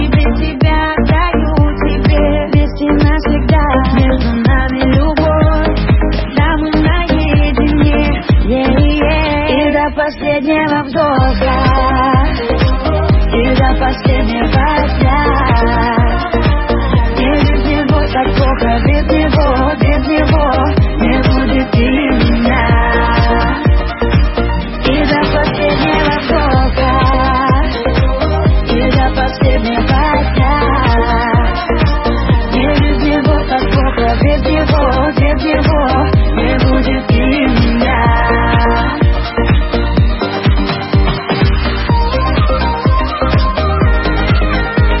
Зона Обмена | Музыка | Русская ПОПса